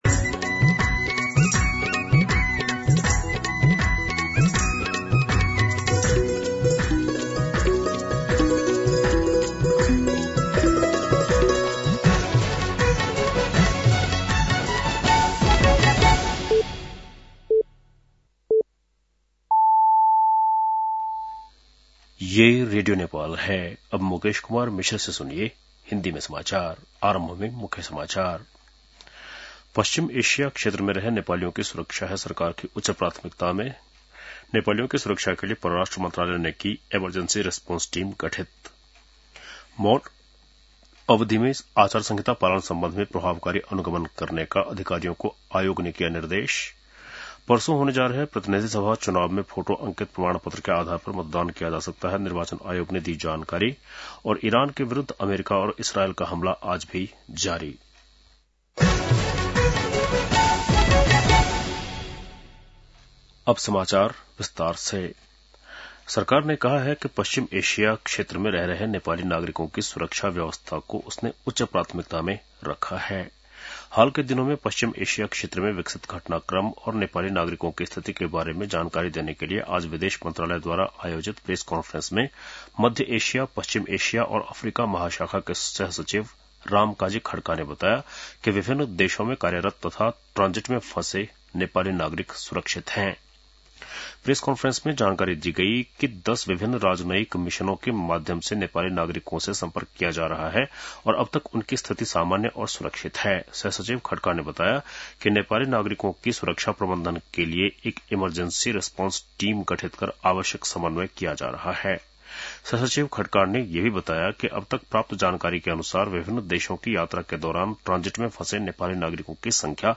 बेलुकी १० बजेको हिन्दी समाचार : १९ फागुन , २०८२